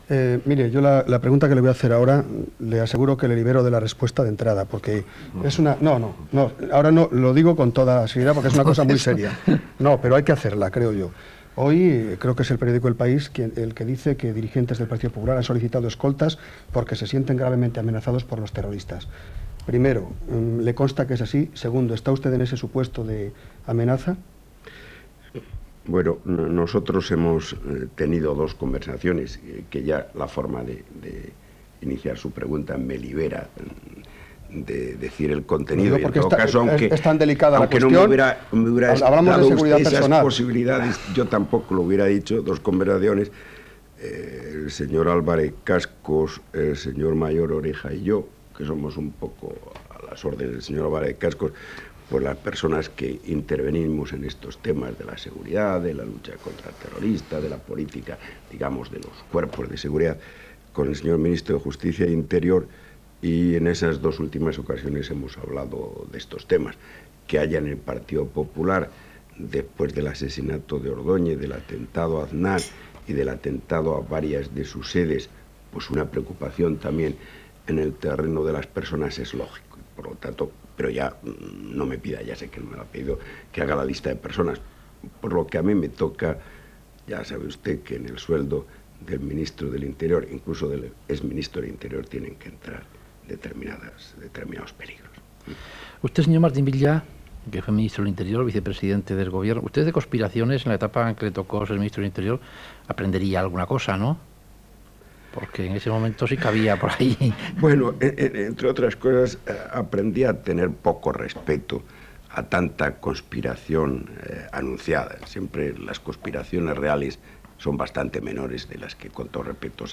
Entrevista al diputat del Partido Popular Rodolfo Martín Villa